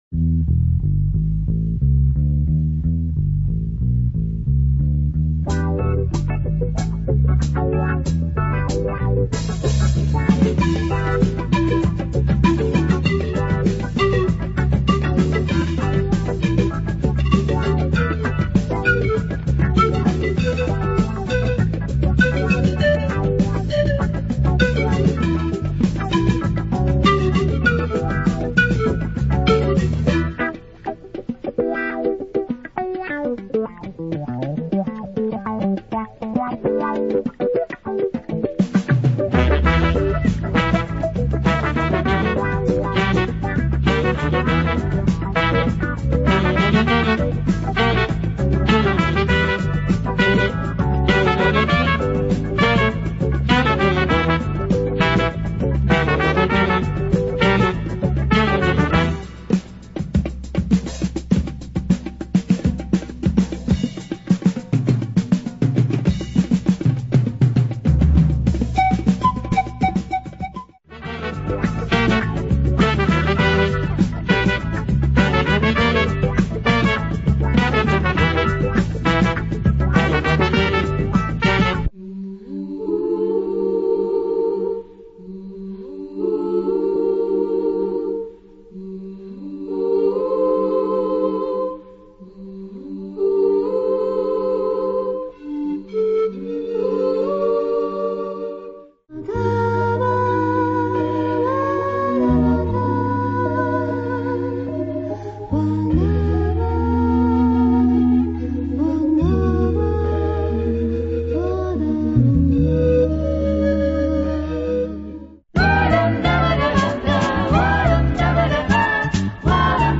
A masterpiece of Romanian jazz funk on panpipe !